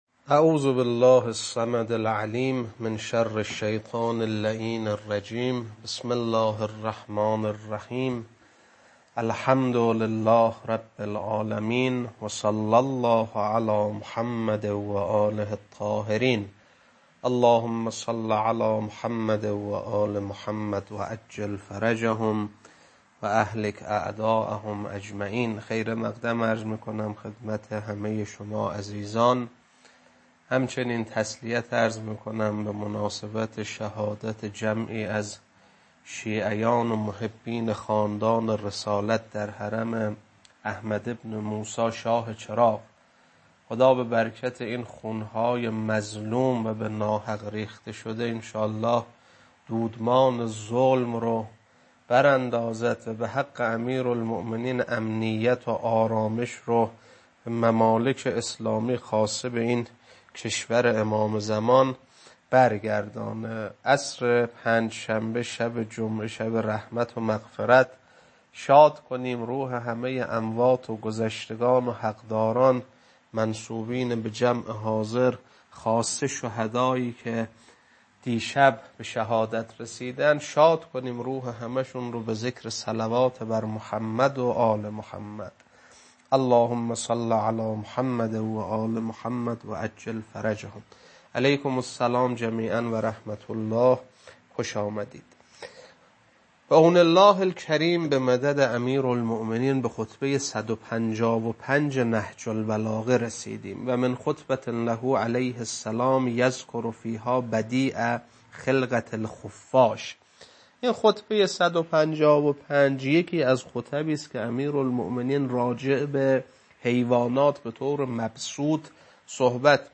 خطبه-155.mp3